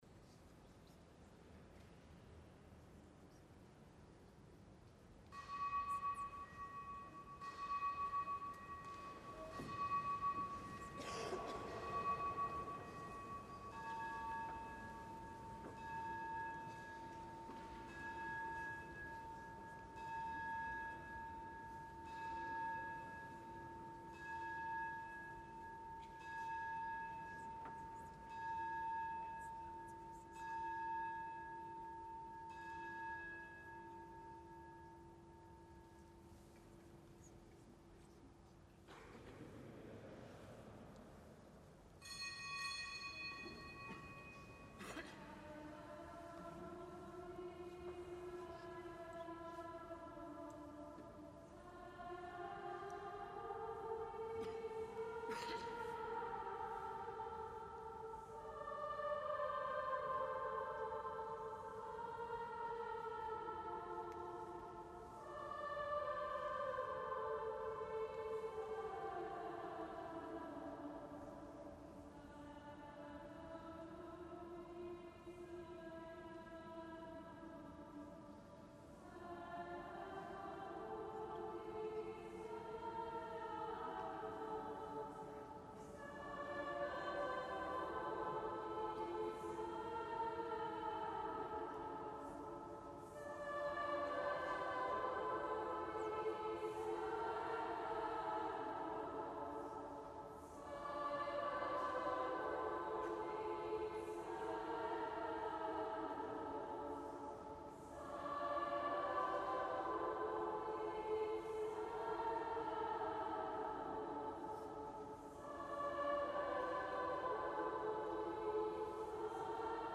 Kapitelsamt am zweiten Fastensonntag
Es sang der Mädchenchor am Kölner Dom